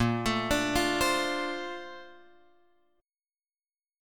A# Major 9th